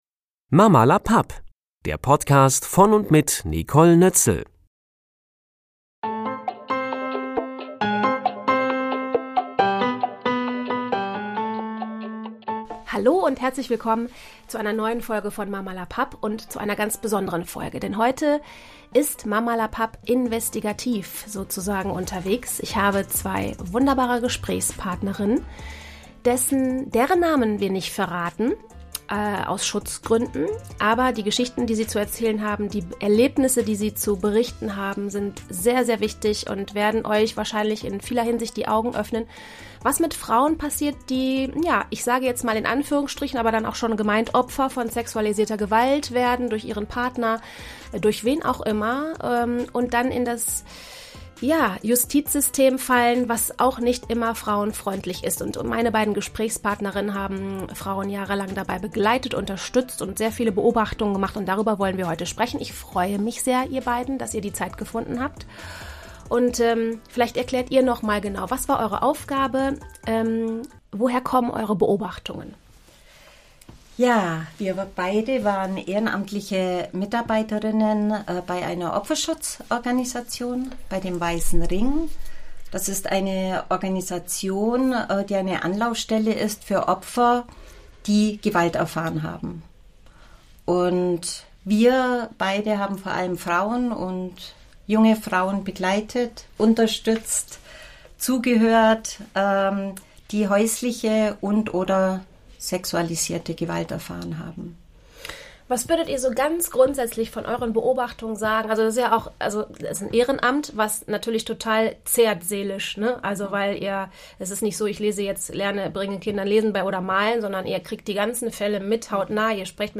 Es geht um Vergewaltigung, es geht um Inzest und es geht darum, wie schwer es Opfer von Gewaltverbrechen haben, wenn sie die Tat zur Anzeige bringen wollen oder sie zur Anzeige gebracht haben. Ich spreche mit zwei Frauen, die jahrelang beim weißen Ring Opfer betreut und sie auch zu den Befragungen und Prozessen begleitet haben.
Zum Schutz ihrer Identität bleiben meine Interviewpartnerinnen anonym.